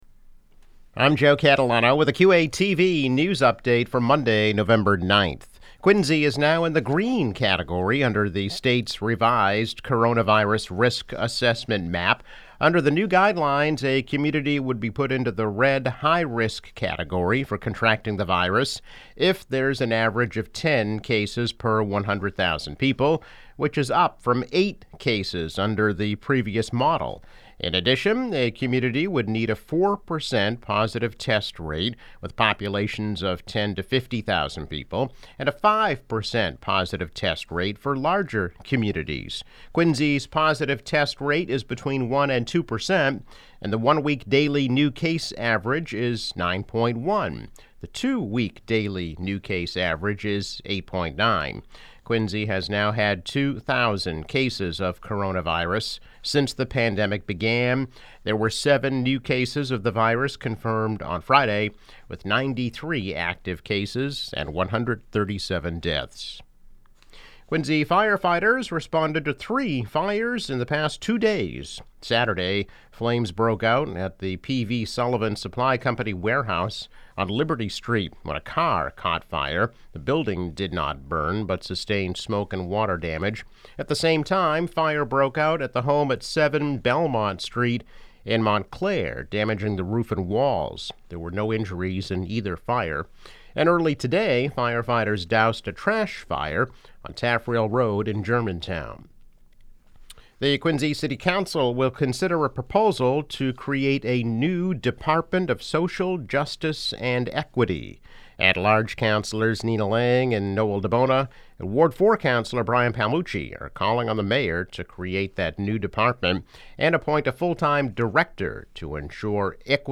News Update - November 9, 2020